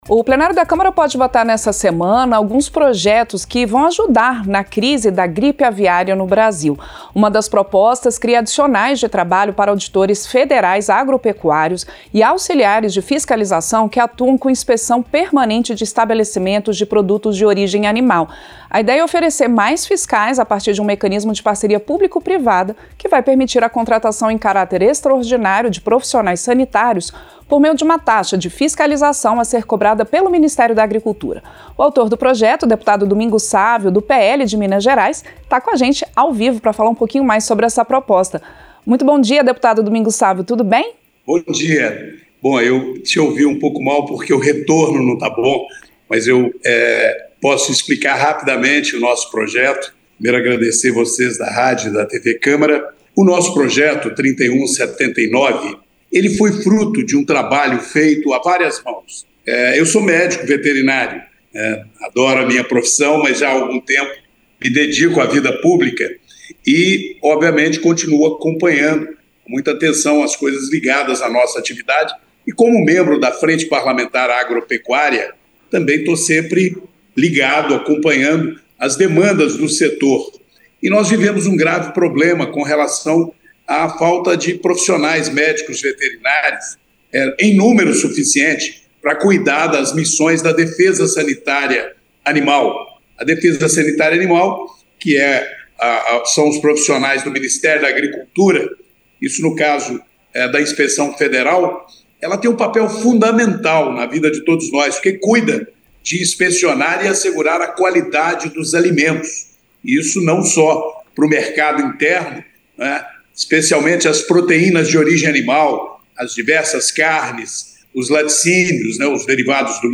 • Entrevista - Dep. Domingos Sávio (PL-MG)
Programa ao vivo com reportagens, entrevistas sobre temas relacionados à Câmara dos Deputados, e o que vai ser destaque durante a semana.